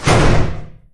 door-close.wav